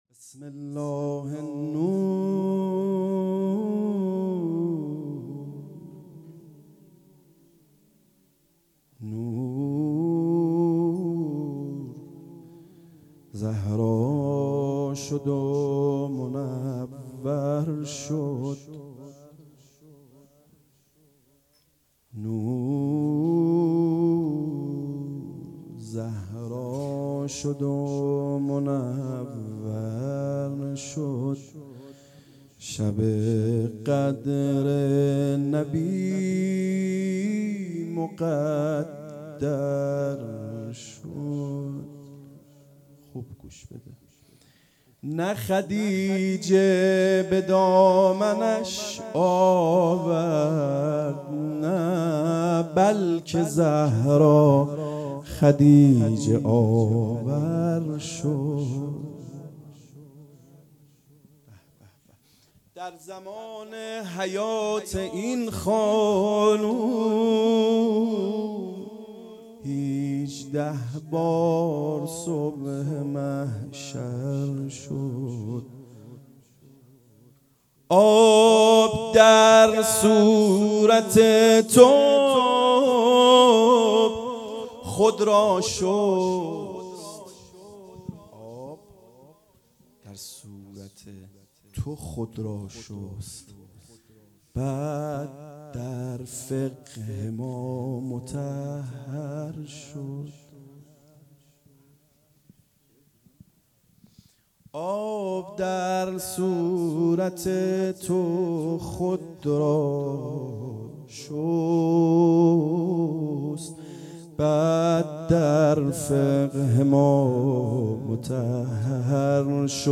مدح - بسم الله نور
جشن ولادت حضرت زهرا سلام الله علیها